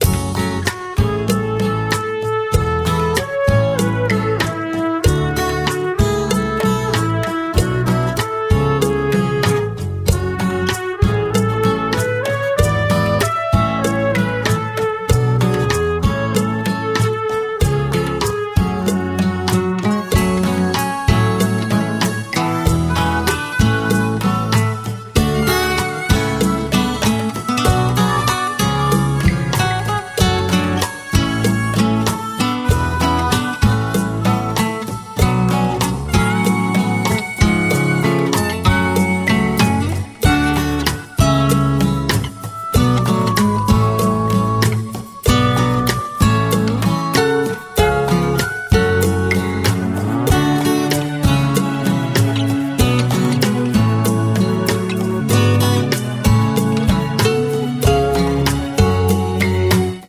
• Качество: 184, Stereo
гитара
грустные
спокойные
без слов
инструментальные
проигрыш на гитаре
Вторая версия компиляции проигрышей композиции.